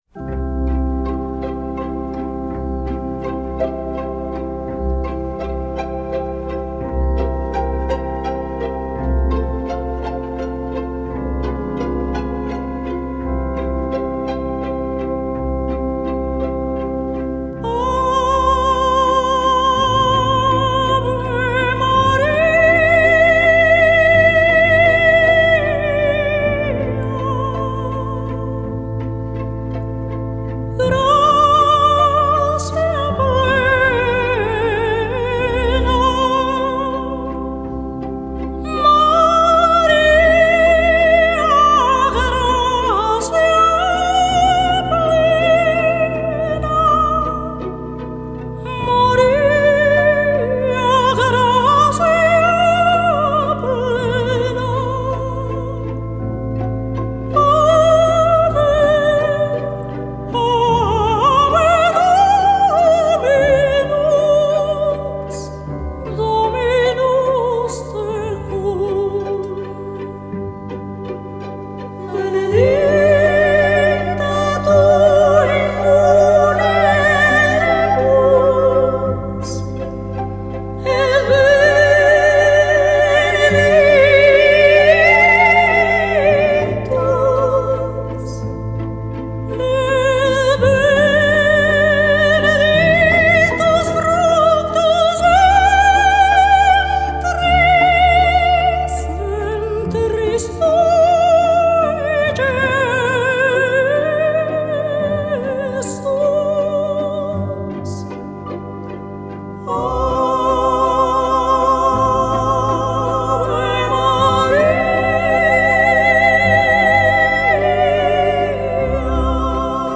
исполнителя популярных оперных арий